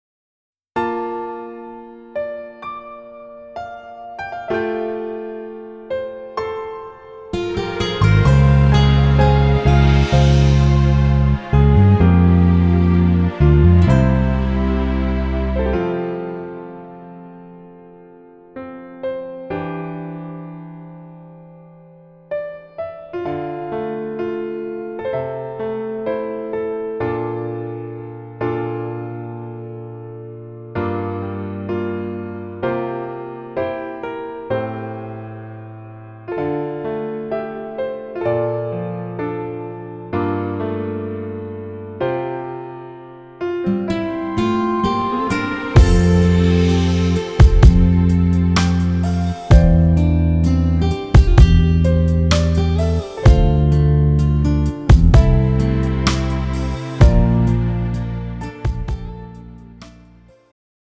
음정 여자-2키
장르 축가 구분 Pro MR